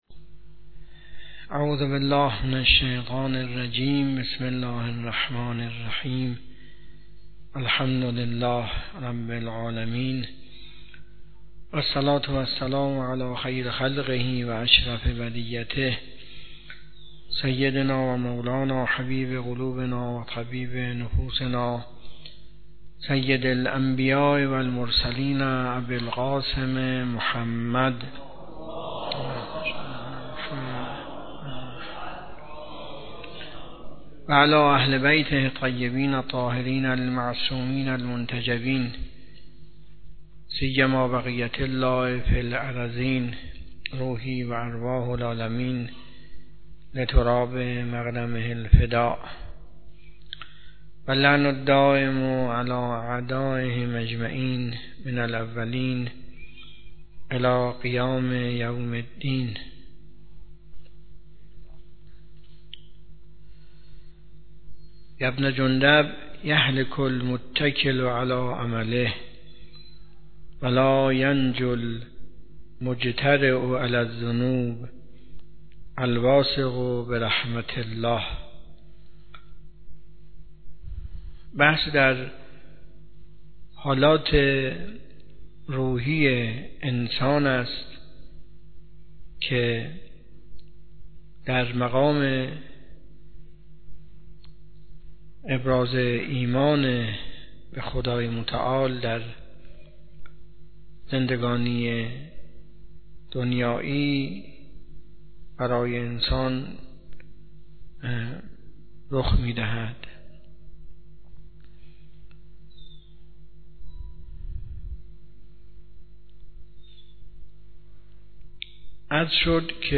حوزه علمیه معیر تهران